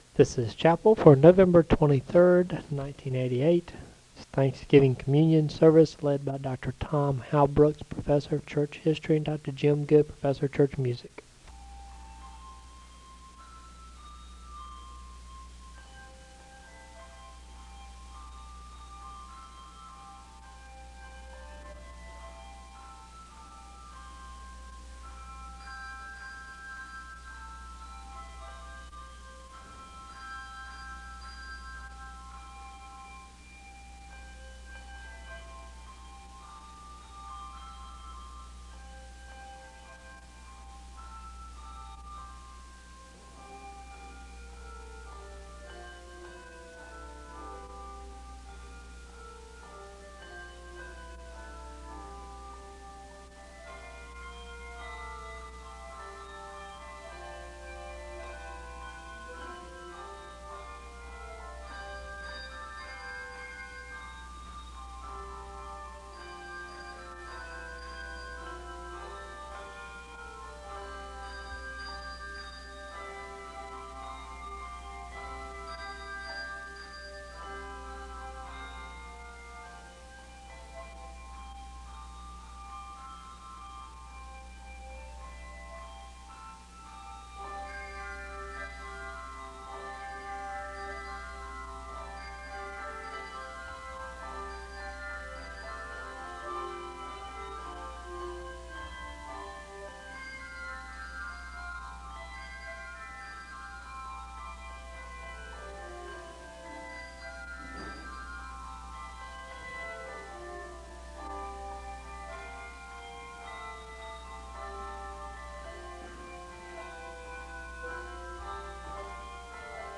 Hymns are played (0:12-6:13). A recitation of worship is given (6:14-6:48). A word of prayer is followed by another hymn (cut) (6:49-7:24). Requests for remembering those going through difficult times during Thanksgiving are made (7:25-9:35).
A litany of thanksgiving is recited (11:45-15:07). An explanation of how communion will commence is provided (15:08-16:08).